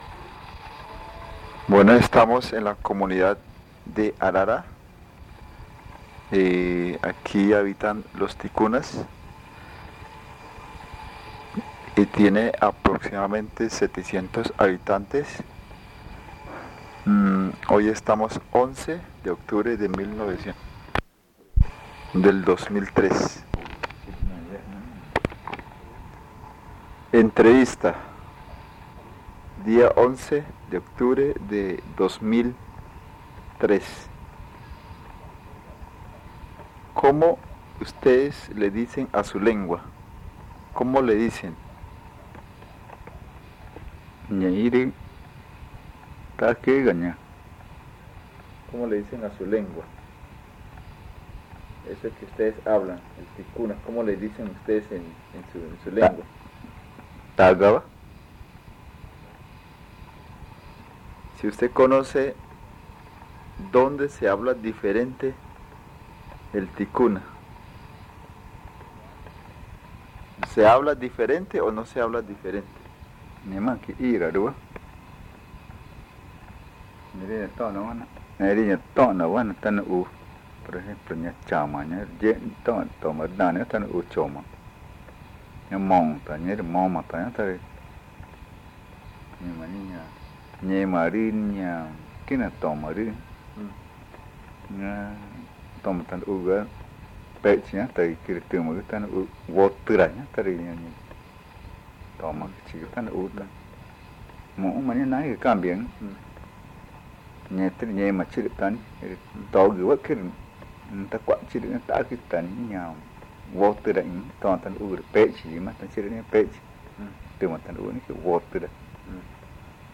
Este casete es el primero de una serie de tres casetes que se grabaron en torno a la variedad magütá hablada en Arara. El audio contiene los lados A y B.